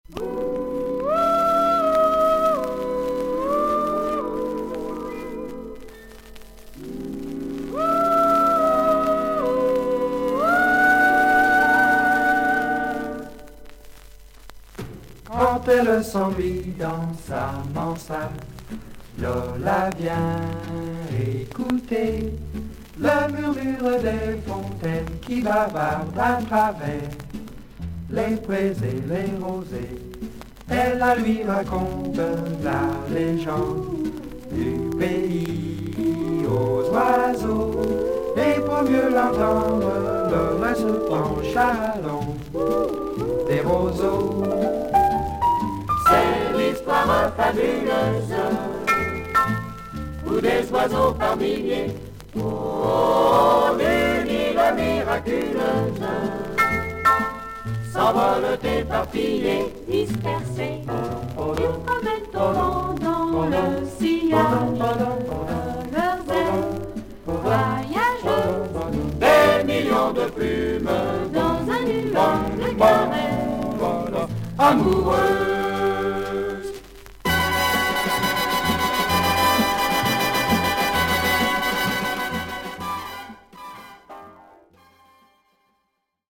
少々軽いパチノイズの箇所あり。全体的に少々サーフィス・ノイズあり。